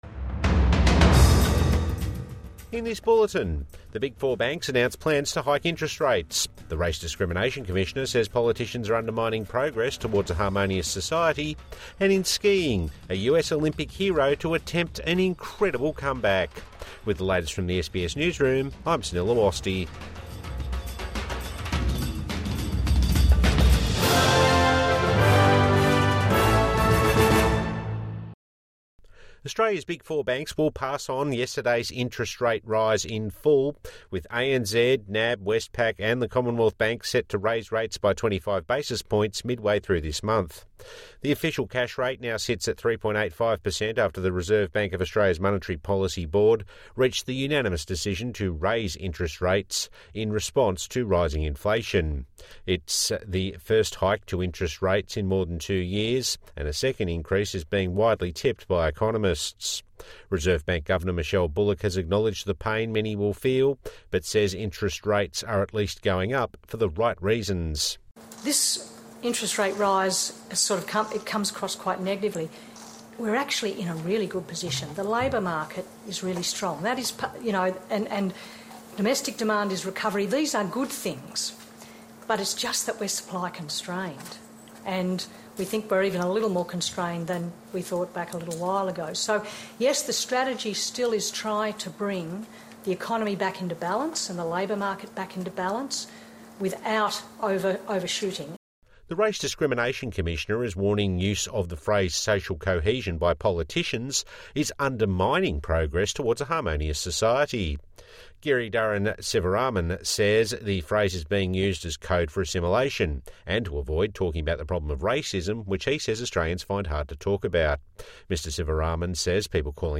The four big banks will pass on the the interest rate rise | Morning News Bulletin 4 February 2026